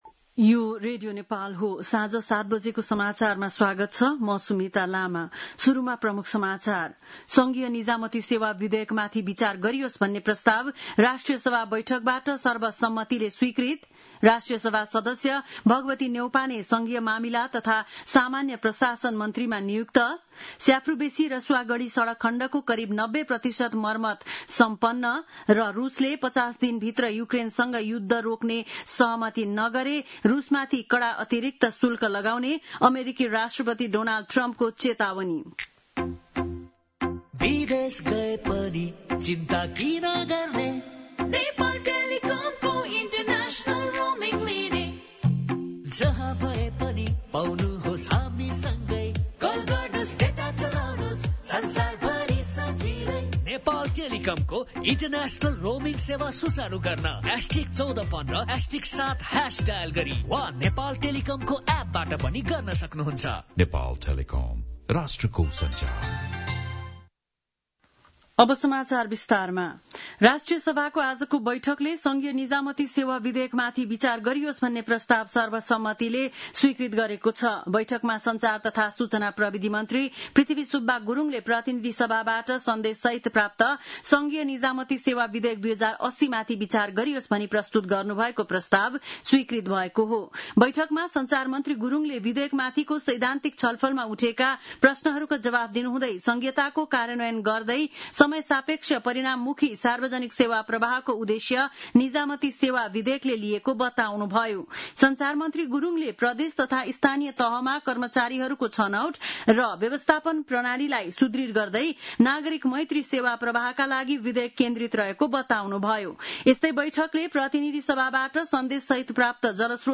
बेलुकी ७ बजेको नेपाली समाचार : ३१ असार , २०८२
7-pm-nepali-news-3-31.mp3